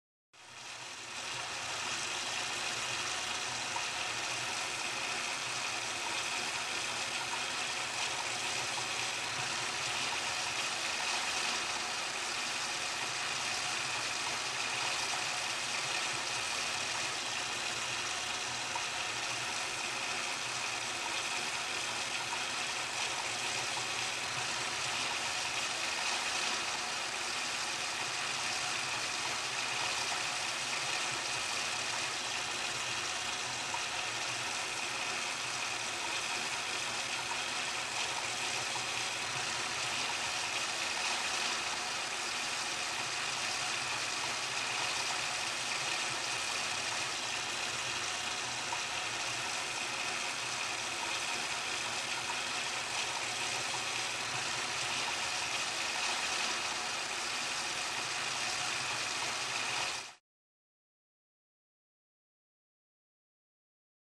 Bathtub - Running Steady Water